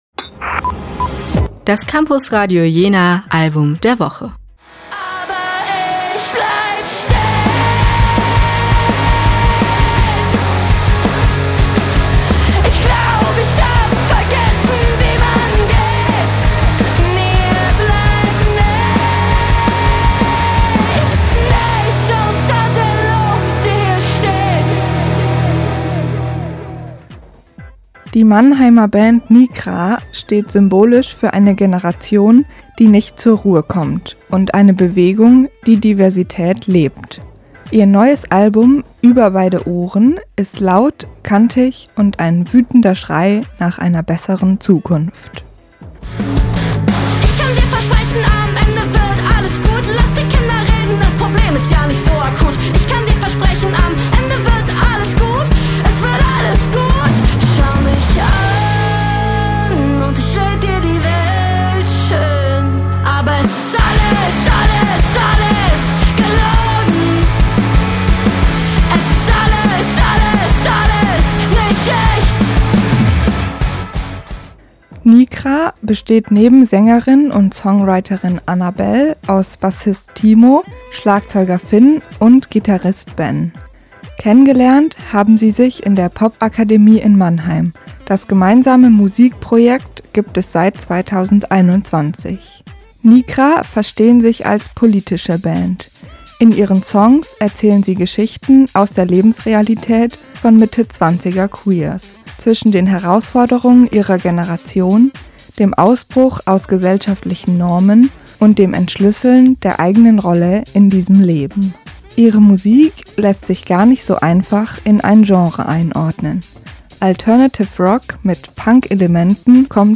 Laut, kompromisslos und politisch aufgeladen nimmt uns die Platte mit in die Lebensrealität von Mitte 20er Queers und ist zugleich eingängig und tanzbar. Das Campusradio liefert euch die Infos zum Album.